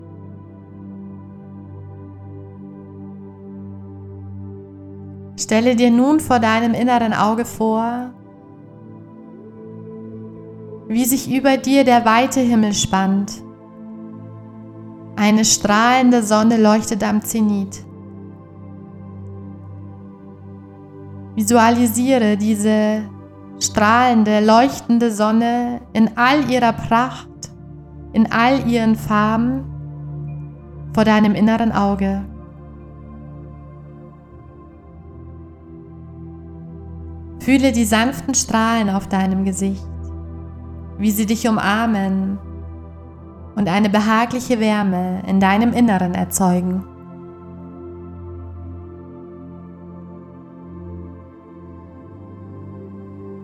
Sonnenjahr Meditation: Lass Dein inneres Licht strahlen: geführte Meditation, Heilsteine: Sonnenstein - Bergkristall - Aventurin. Mp3-Download.